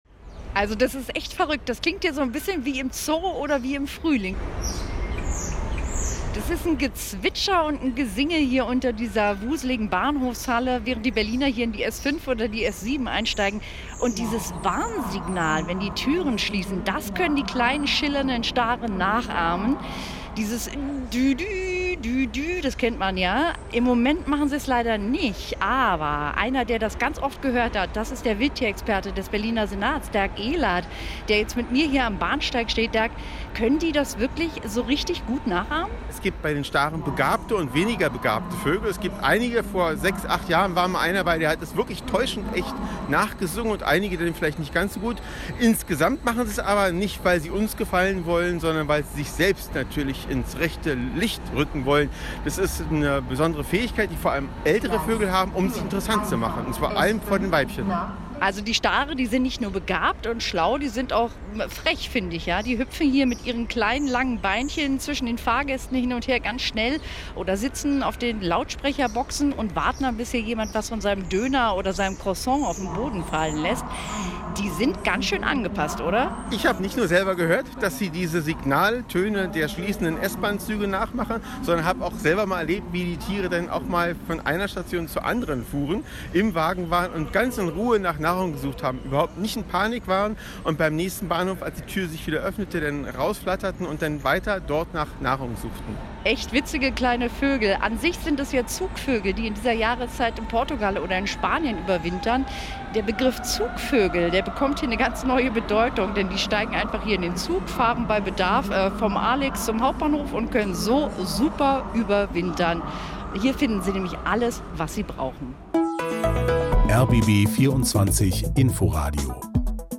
Sie kennen das typische Piepen, das die S-Bahnen machen, wenn die Türen schließen.
Sondern es gibt Vögel - genauer gesagt Stare - die das täuschend echt nachmachen können.